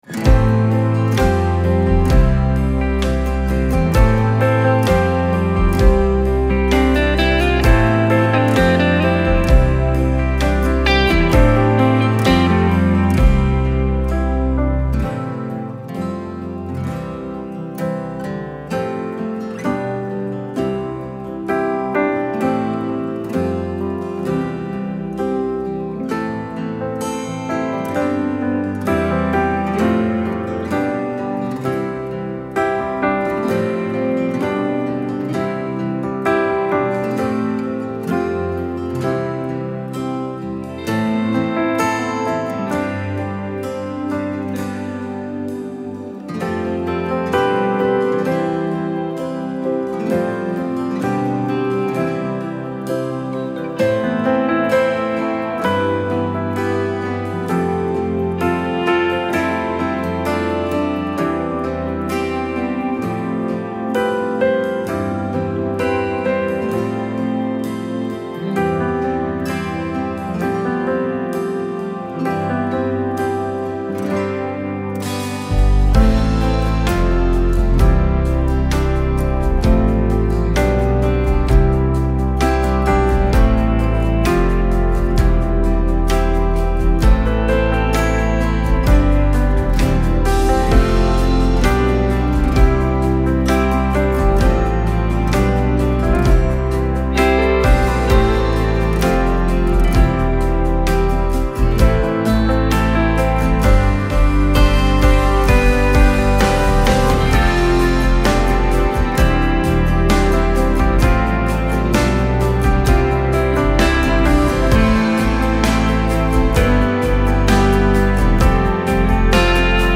Titre Version chantée Version instrumentale 2.